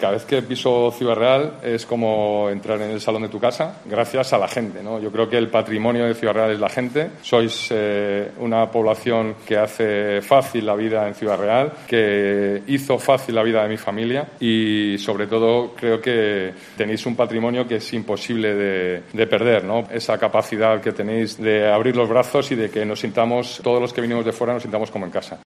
en el Salón de Plenos del Ayuntamiento de Ciudad Real